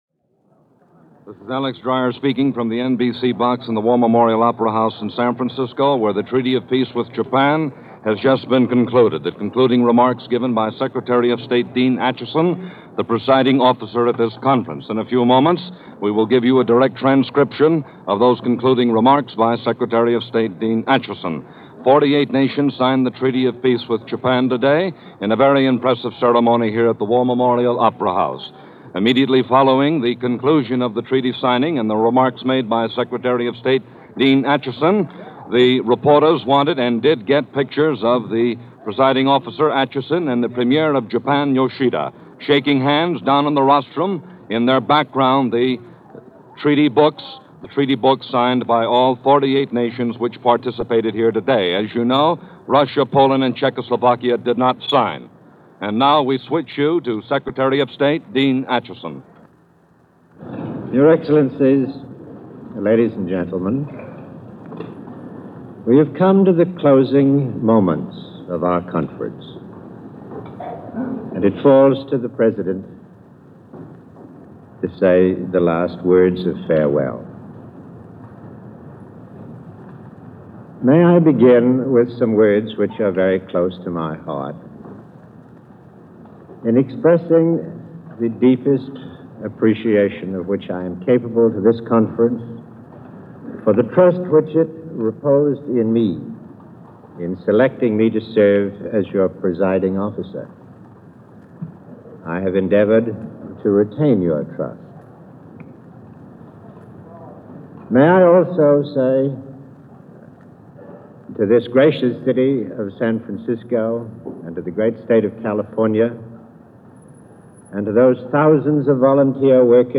September 8, 1951 - A Peace Treaty Signed - World War 2 Officially Ends - report from San Francisco - signing of Peace Treaty With Japan.
This special broadcast, from NBC Radio also includes a commentary and several references to the Gromyko objections. Here is that ceremony and the discussion as it happened.